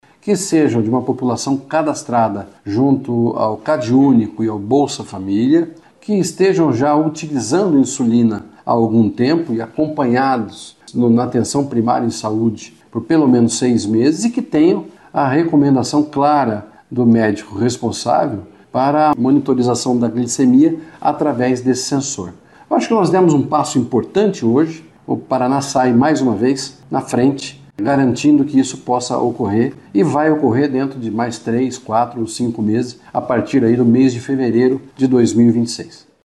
Beto Preto, secretário da Saúde do Paraná, disse que a distribuição obedecerá a critérios definidos na legislação estadual, para que o paciente tenha acesso ao sensor.